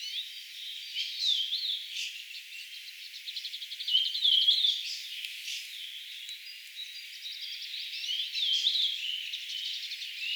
tuolla tavoin laulava punakylkirastas
tuolla_tavoin_laulava_punakylkirastas_uusi_laulutyyppi.mp3